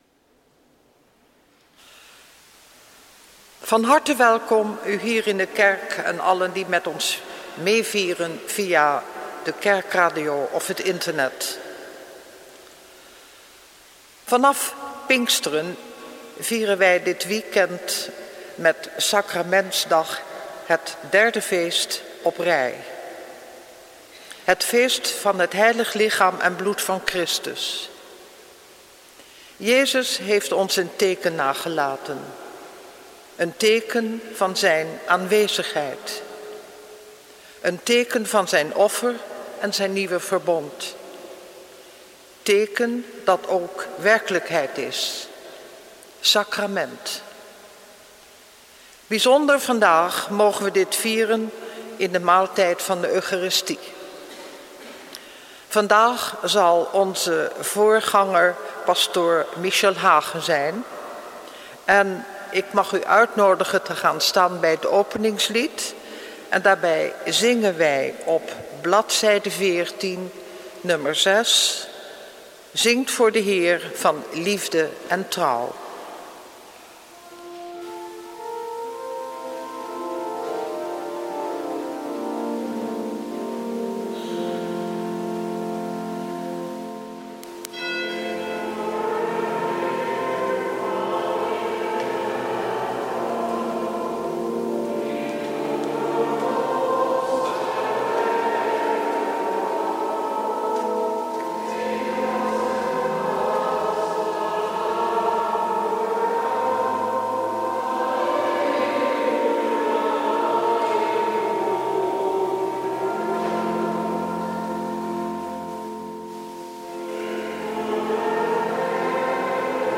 Eucharistieviering beluisteren (MP3)